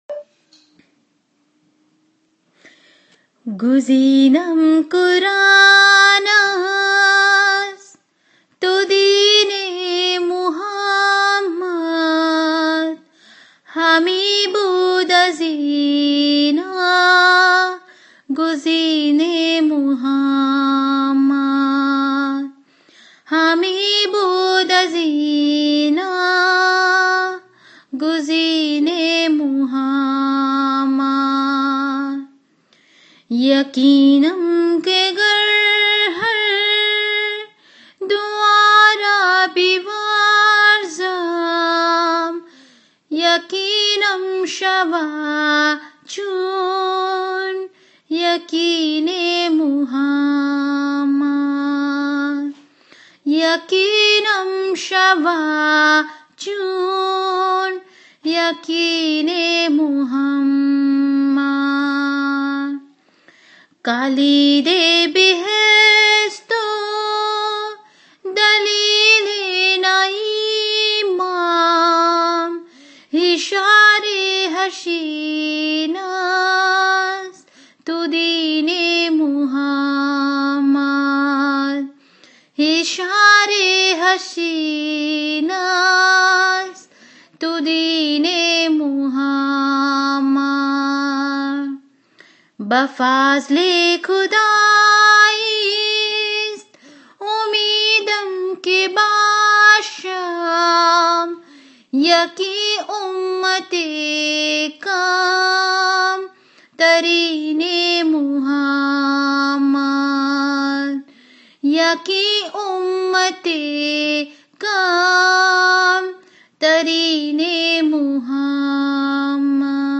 Author: Nasir Khusraw